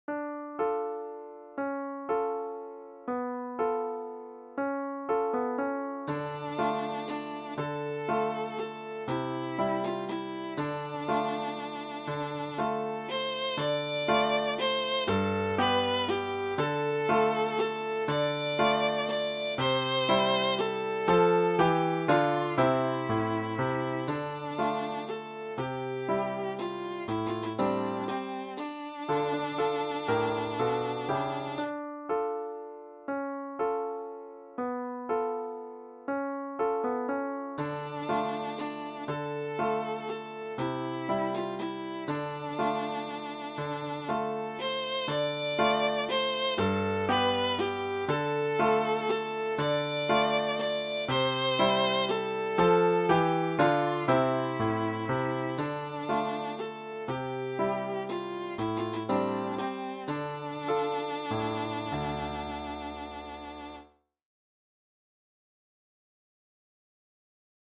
Easy Viola Solo with Piano Accompaniment